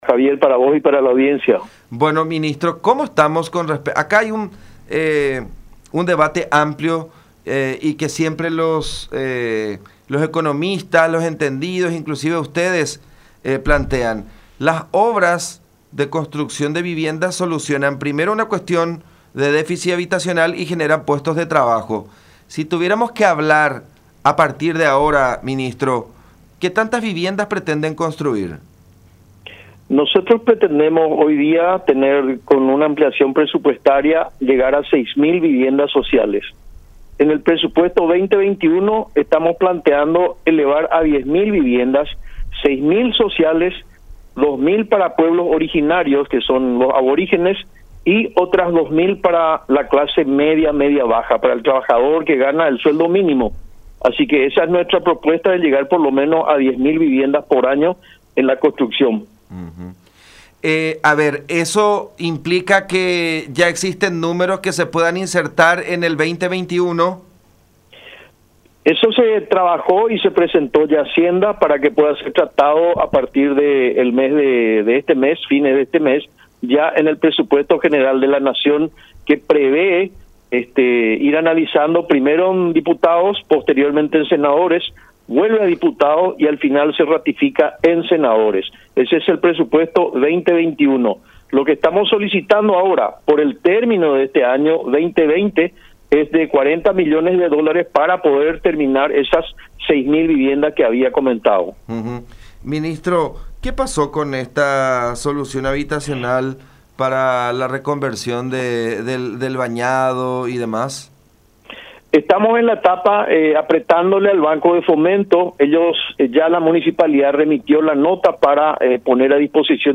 El ministro de Urbanismo, Vivienda y Hábitat, Dany Durand, afirmó que seguirán sirviéndose de la mano de obra de las pequeñas y medianas empresas de la construcción, "aguantando la presión" de las grandes corporaciones de ese rubro, como la CAPACO y la CAVIALPA.